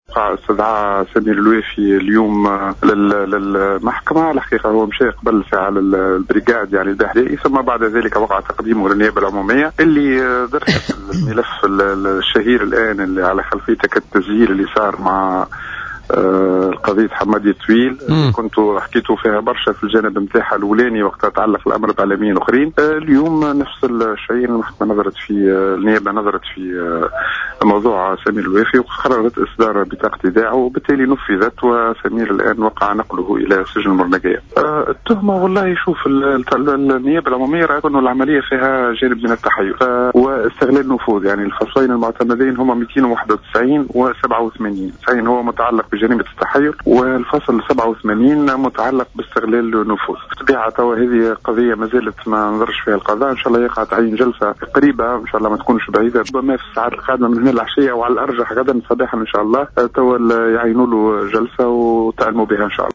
في تدخل هاتفي في برنامج بوليتكا